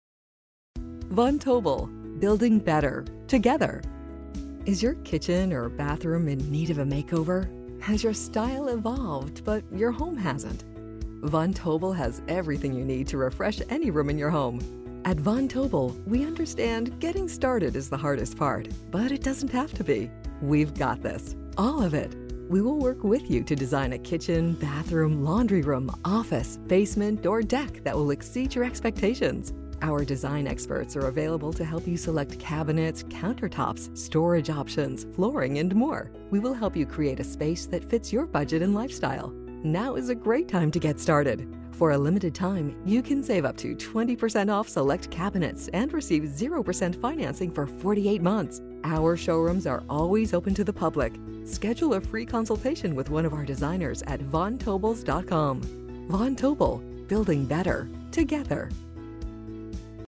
Von Tobel RADIO Advertising